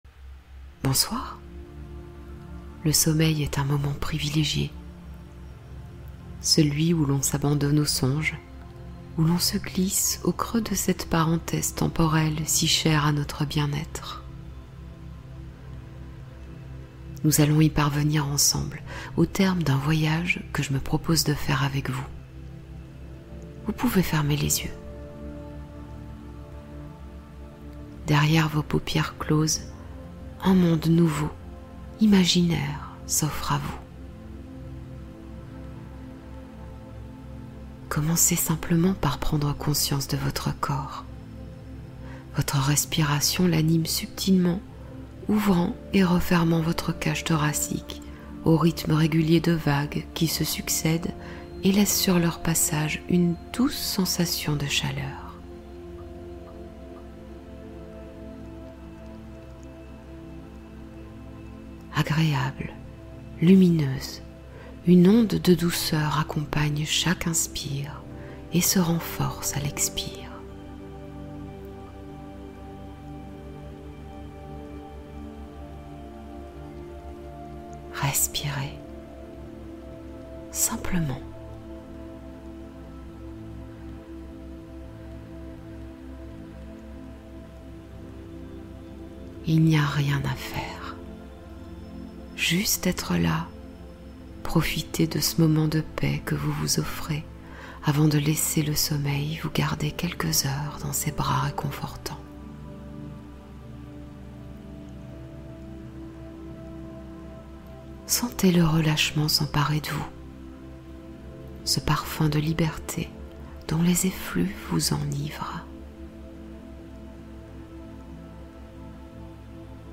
Hypnose de sommeil : soin énergétique pour apaiser l’esprit